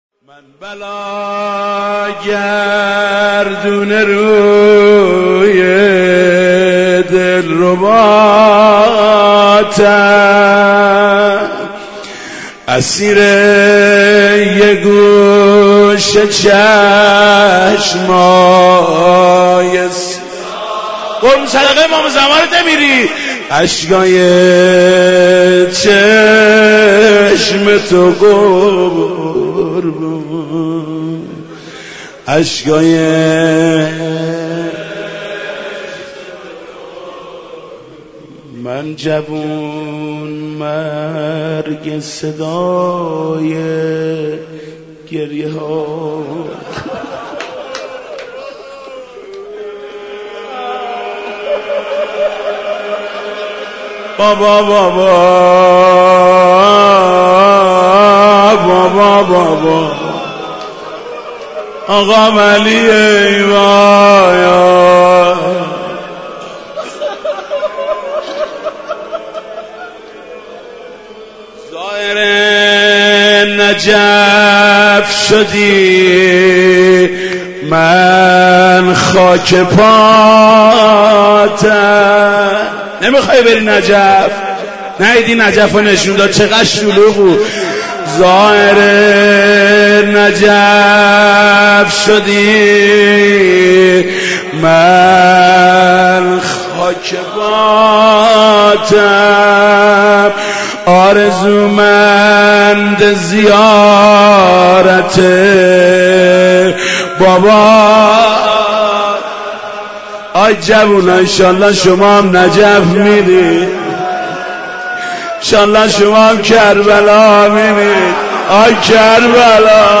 مناجات با امام زمان عجل الله